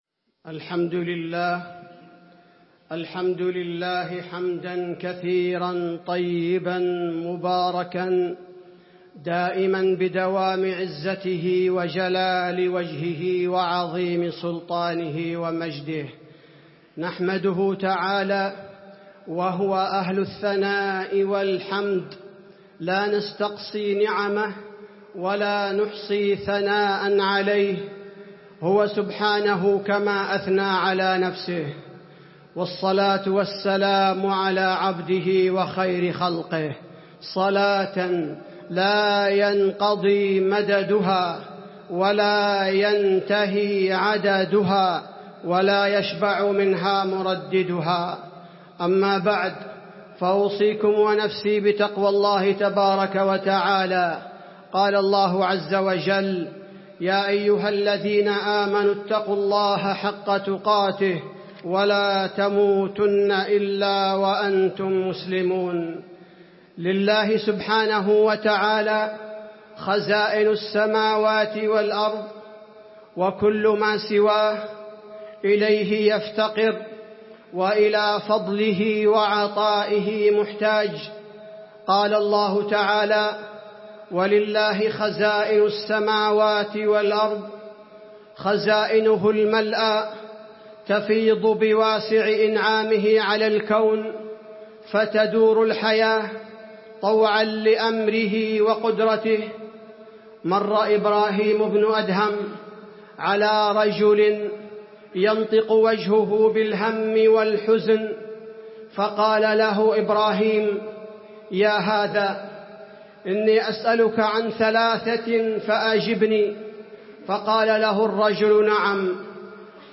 خطبة الاستسقاء - المدينة- الشيخ عبدالباري الثبيتي